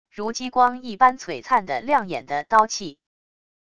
如激光一般璀璨的亮眼的刀气wav音频